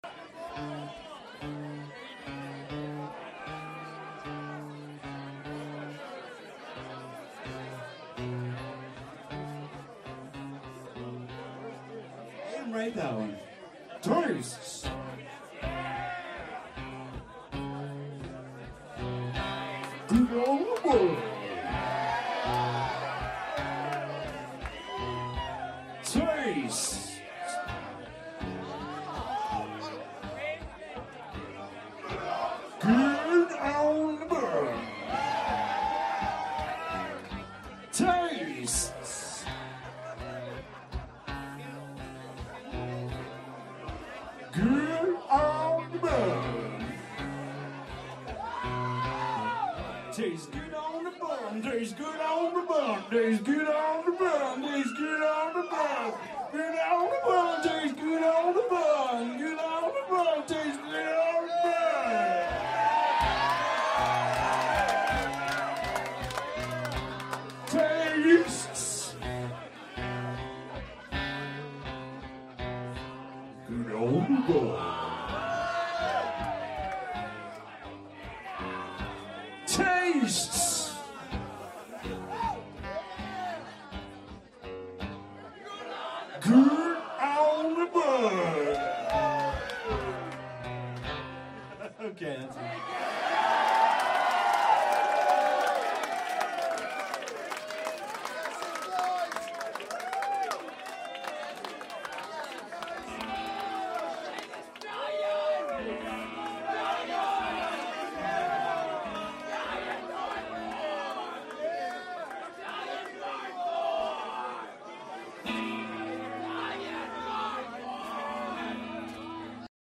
solo acoustic
7th Street Entry MPLS